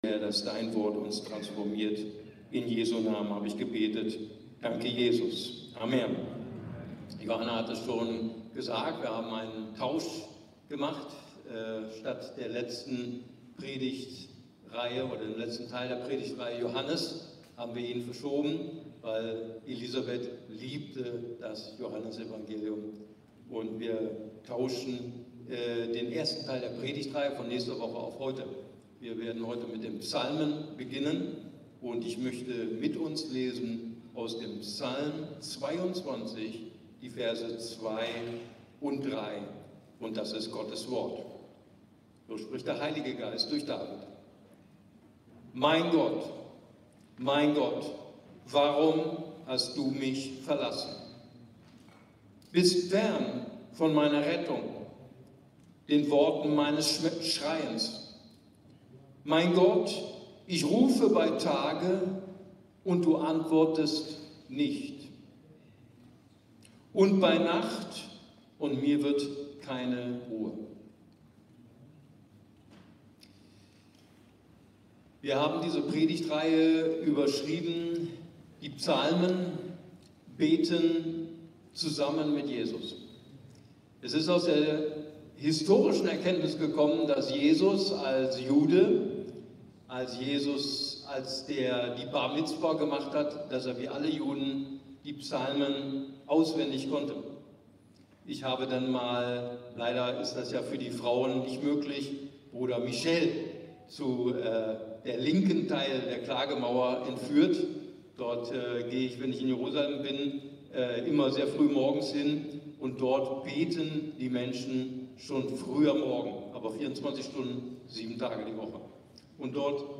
ICB Predigtreihe